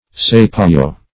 sapajo - definition of sapajo - synonyms, pronunciation, spelling from Free Dictionary Search Result for " sapajo" : The Collaborative International Dictionary of English v.0.48: Sapajo \Sap"a*jo\, n. (Zool.)